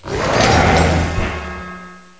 uncomp_zamazenta_crowned_shield.aif